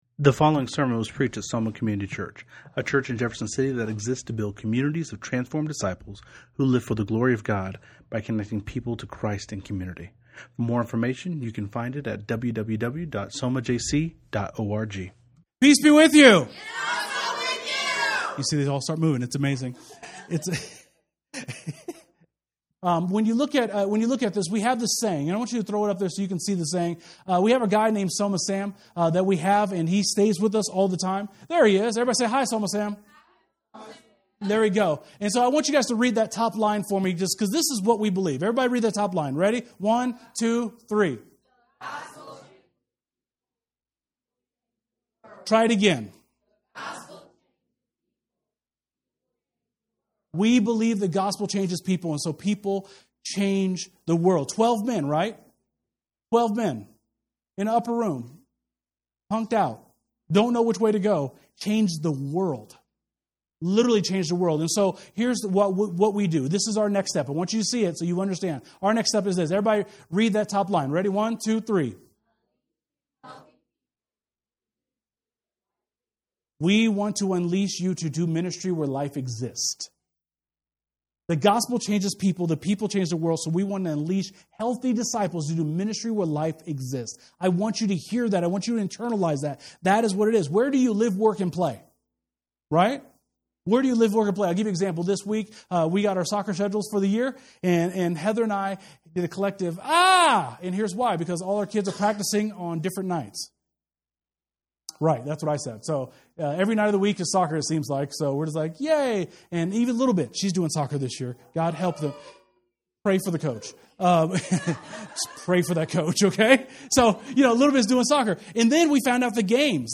Series: Vision Sunday